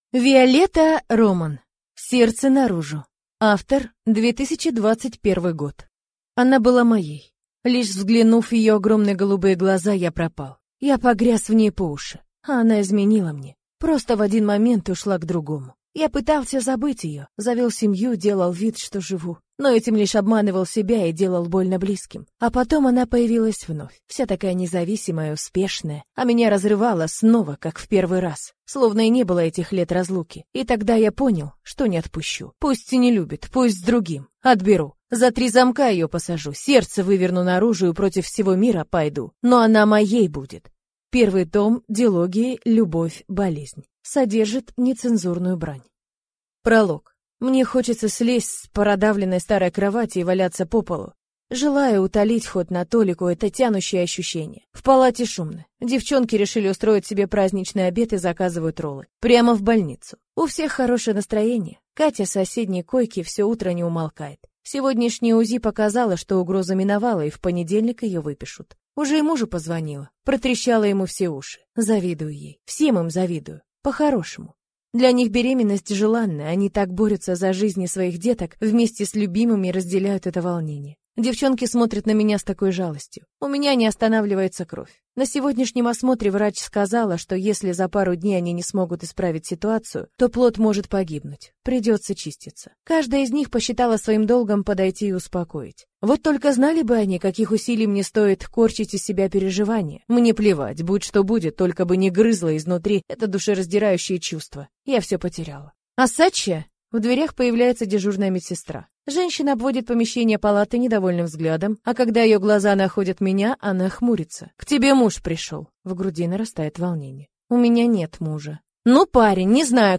Аудиокнига Сердце наружу | Библиотека аудиокниг